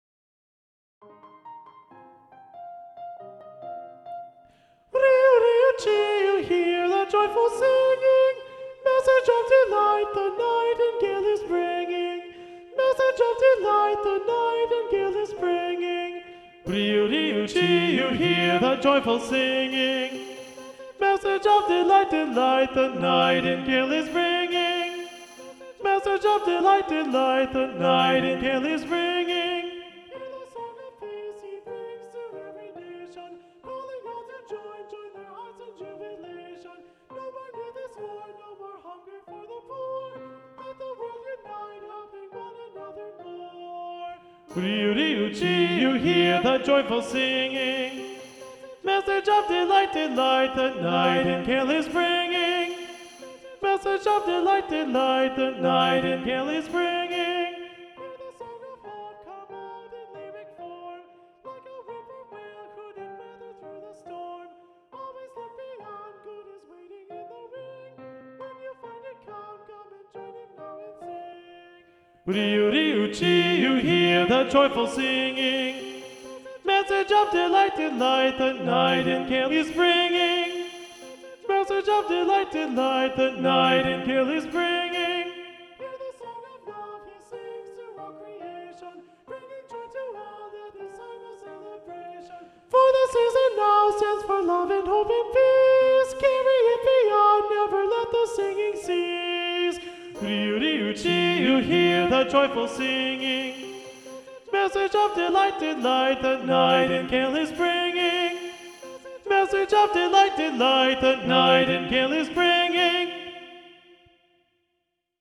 SSA (in English) – Alto Predominant